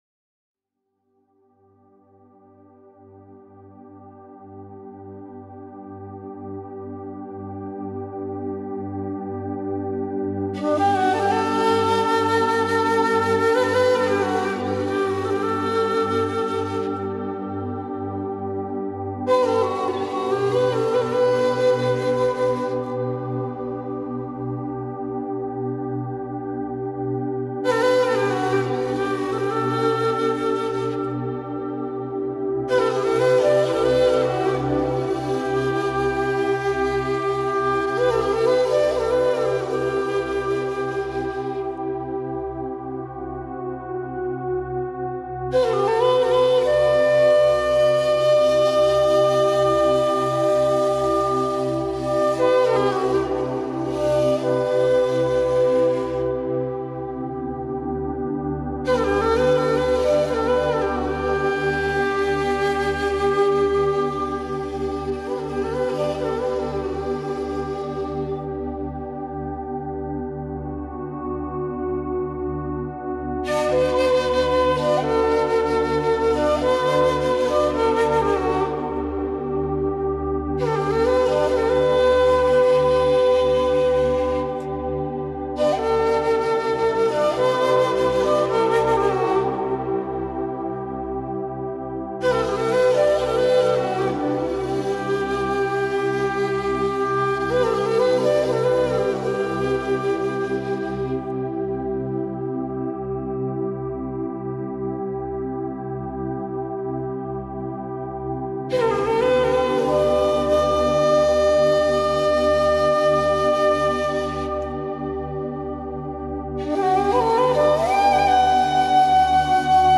Musique_indienne_relaxante1.mp3